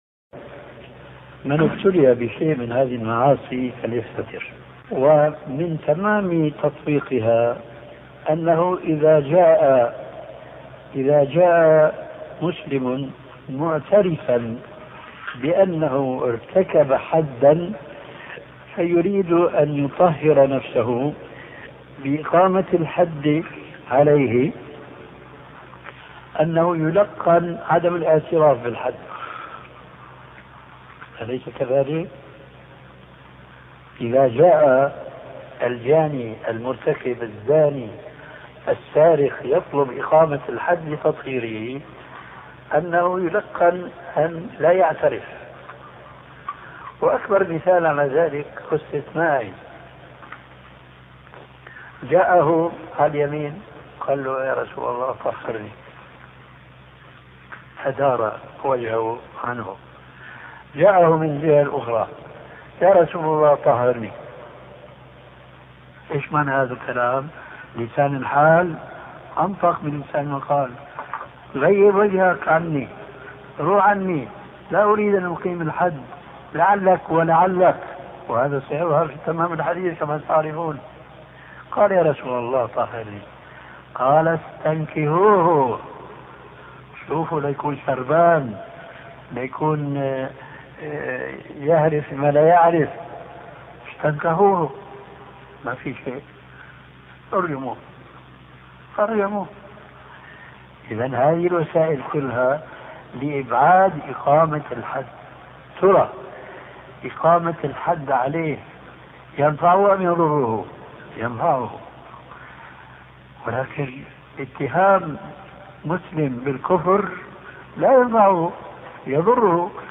شبكة المعرفة الإسلامية | الدروس | التحذير من فتنة التكفير 3 |محمد ناصر الدين الالباني